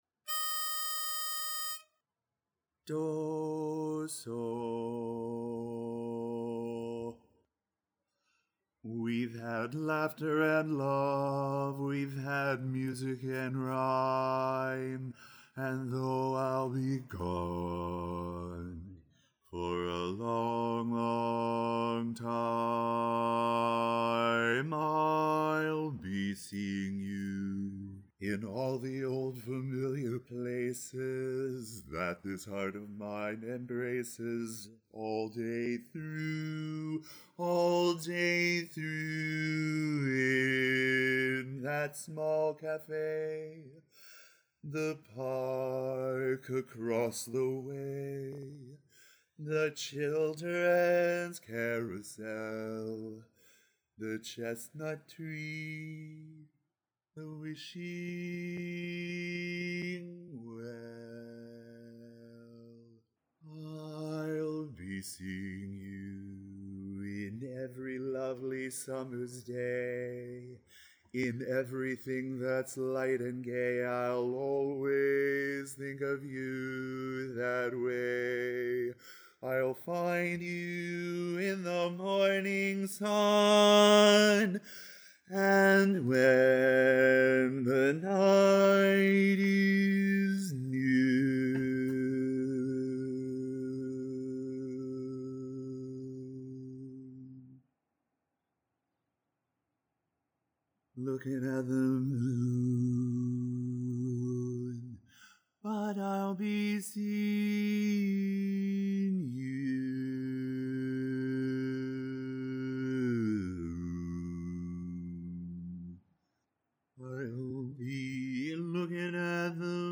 Ballad
Barbershop
E♭ Major
Bass